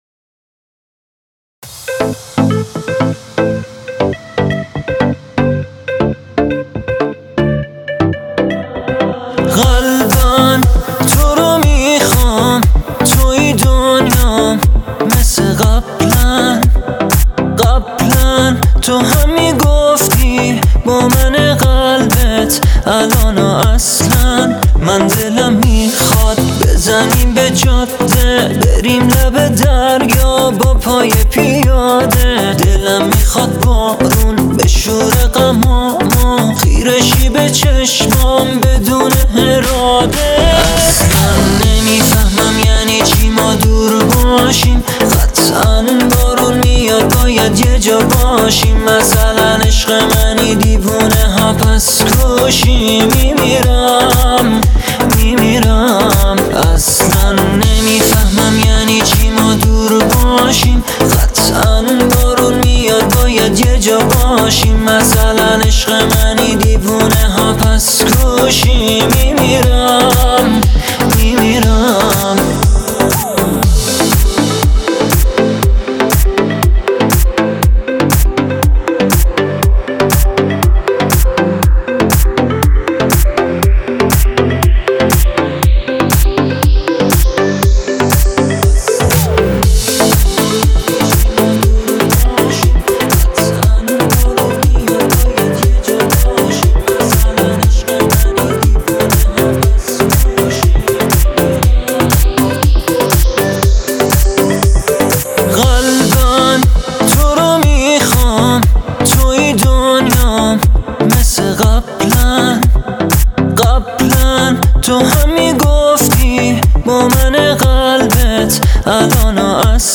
با ریتم 2/4